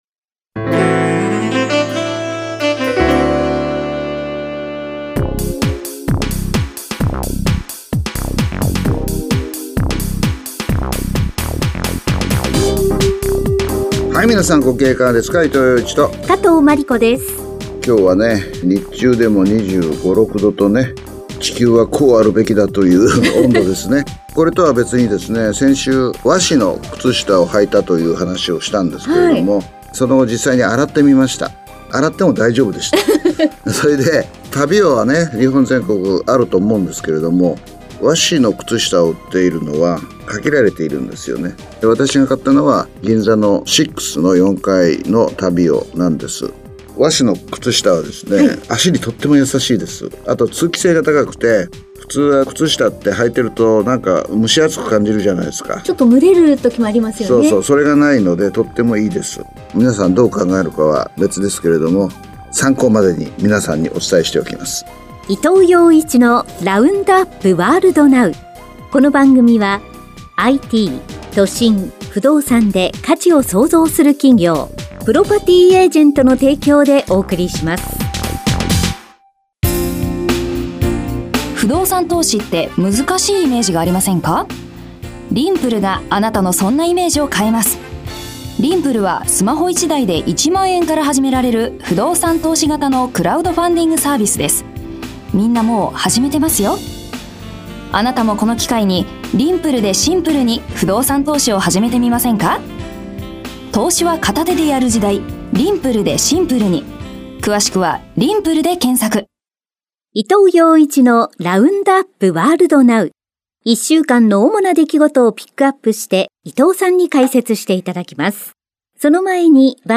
… continue reading 466 에피소드 # ニューストーク # ニュース # ビジネスニュース # NIKKEI RADIO BROADCASTING CORPORATION